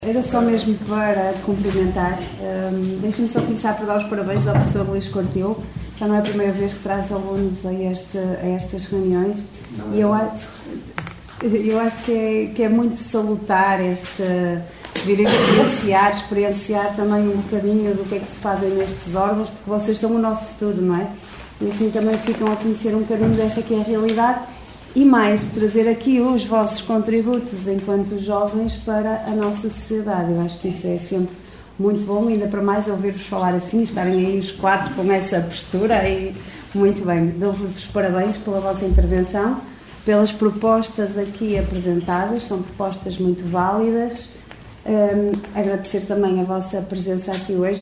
Excertos da última reunião do executivo caminhense realizado no dia 2 de abril.